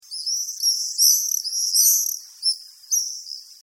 Отличного качества, без посторонних шумов.
468_swallows.mp3